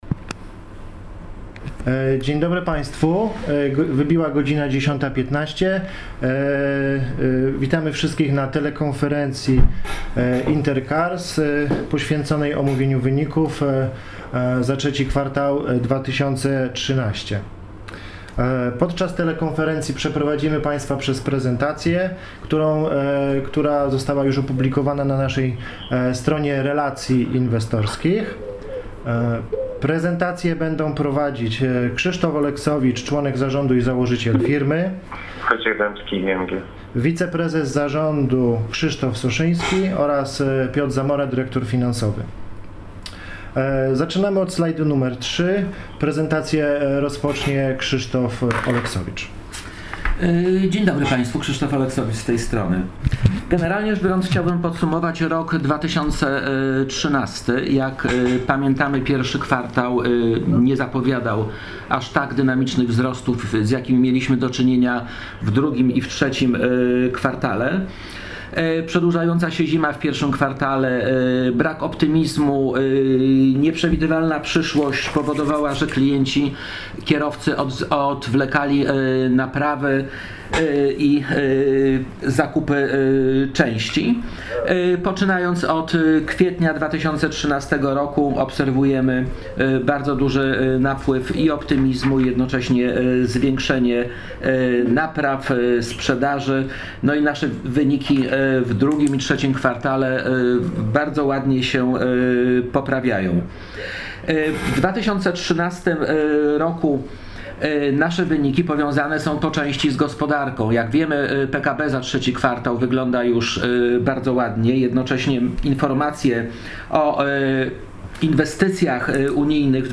Druga telekonferencja z udziałem władz Spółki Inter Cars SA | Relacje Inwestorskie - Inter Cars SA
Poniżej znajdziecie Państwo plik z zarejestrowanym przebiegiem całego spotkania